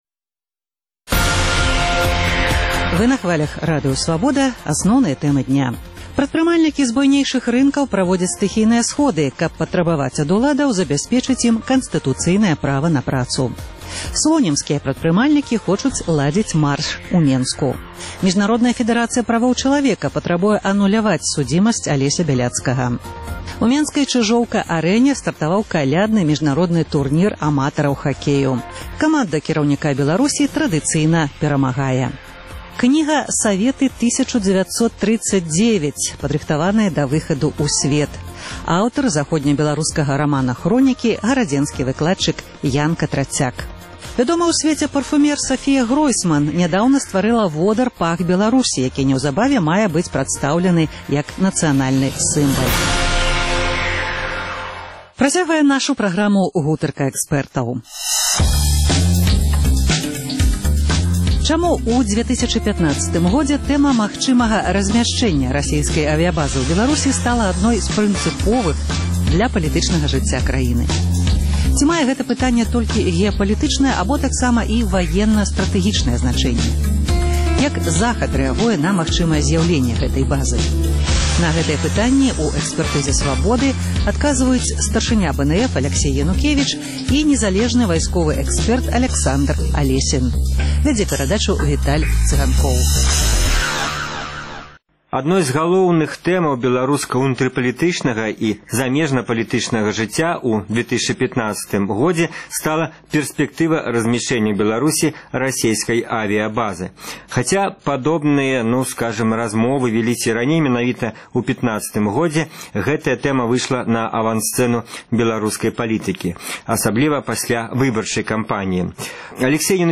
незалежны вайсковы экспэрт